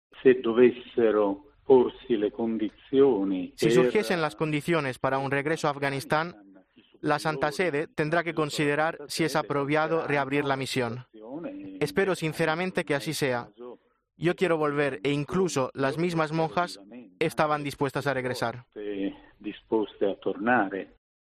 su voz emocionada recuerda su vida allí con muchas limitaciones.